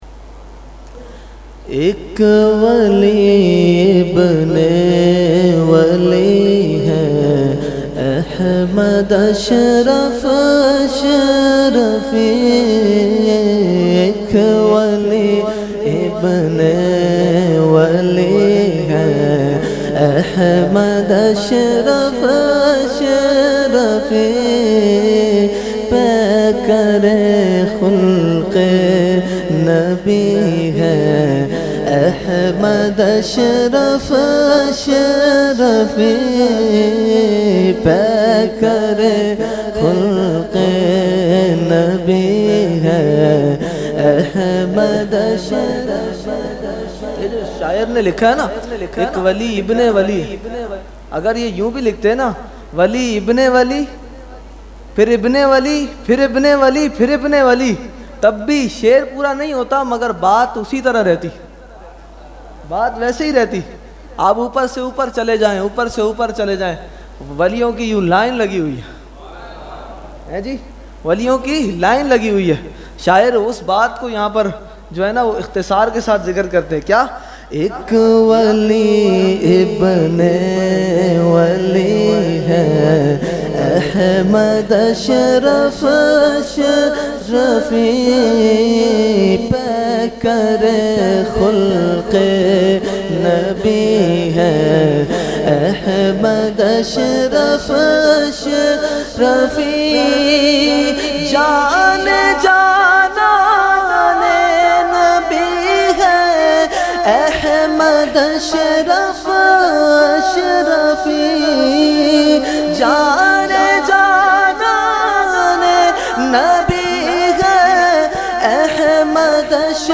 Category : Manqabat | Language : UrduEvent : Urs Ashraful Mashaikh 2020